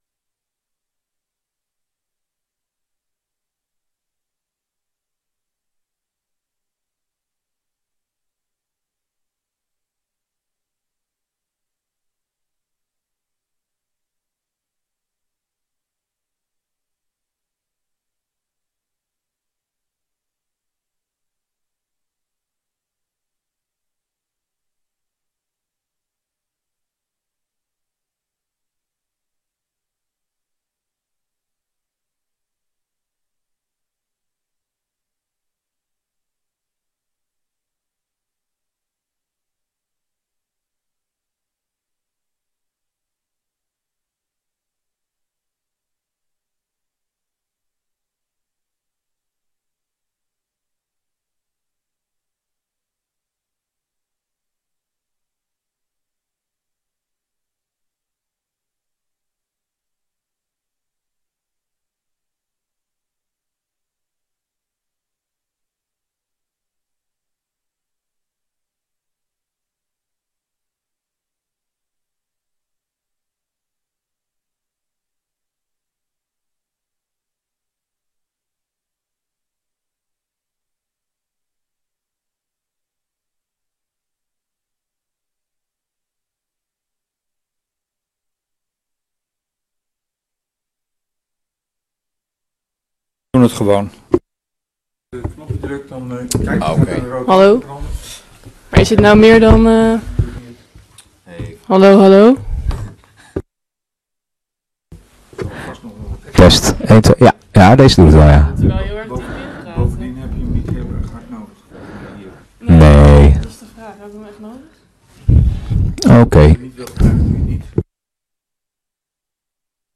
BOB-avond beeld- en oordeelsvormende sessies 16 april 2025 19:30:00, Gemeente Leusden
Download de volledige audio van deze vergadering